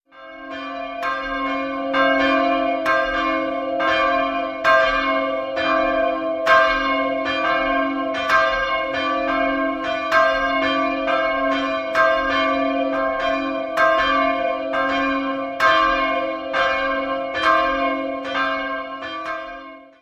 Seit dem Jahr 1716 besitzt der Turm seine heutige Höhe und das barocke Aussehen. 2-stimmiges Große-Sekund-Geläute: c''-d'' Die größere Glocke wurde im Jahr 1515 in der Nürnberger Gießhütte gegossen, die kleinere stammt von Hilibrant Weigel (Ingolstadt) aus dem Jahr 1571.